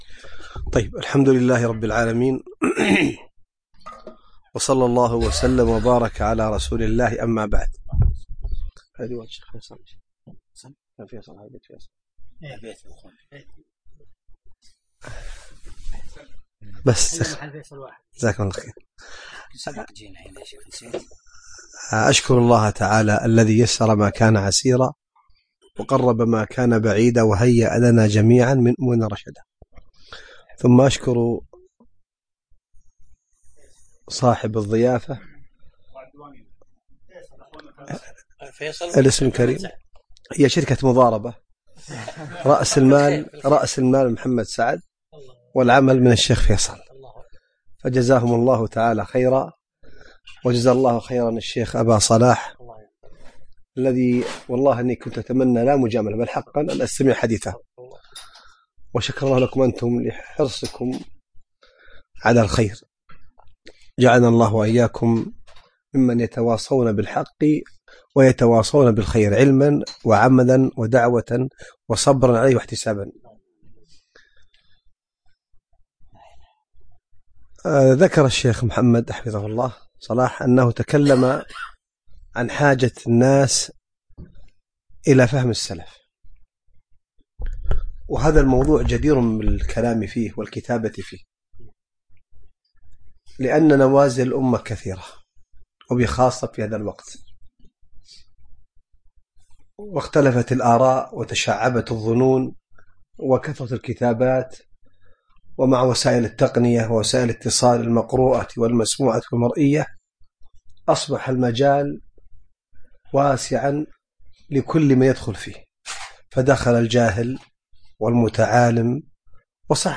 كلمة عن نوازل الأمة